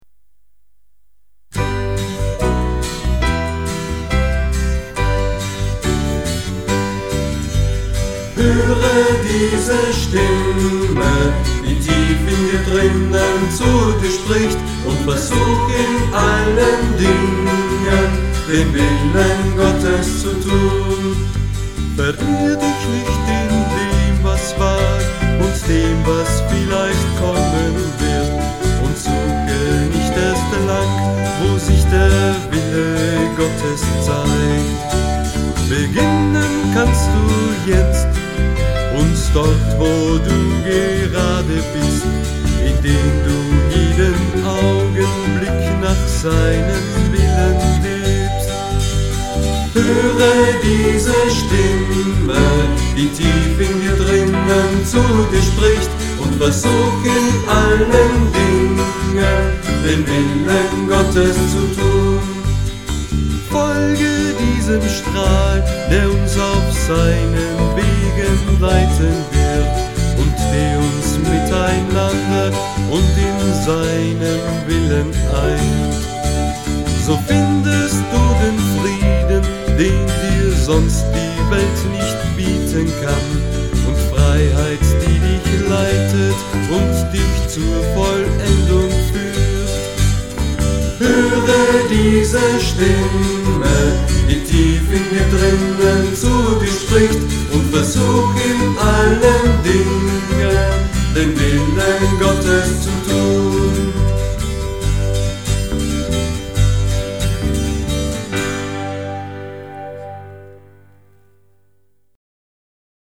Lieder mit Chorsätzen